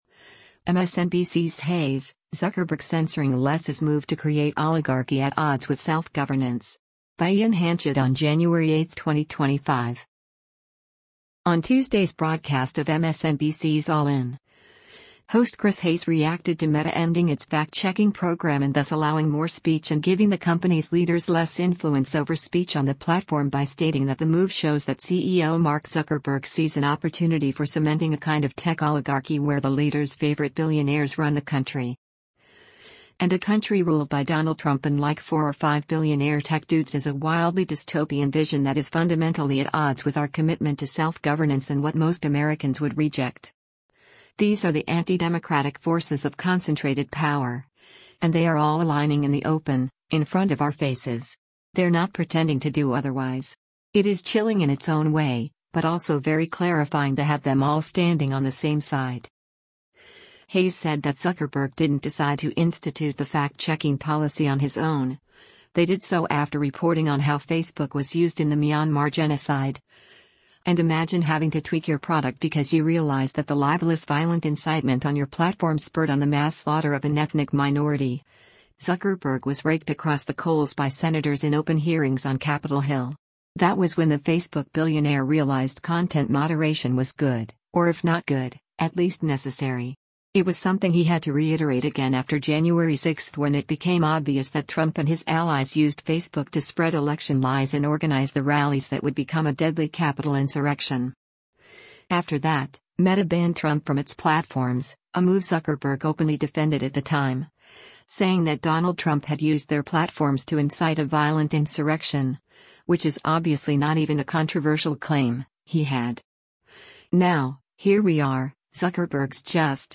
On Tuesday’s broadcast of MSNBC’s “All In,” host Chris Hayes reacted to Meta ending its fact-checking program and thus allowing more speech and giving the company’s leaders less influence over speech on the platform by stating that the move shows that CEO Mark Zuckerberg sees “an opportunity for cementing a kind of tech oligarchy where the leaders’ favorite billionaires run the country.”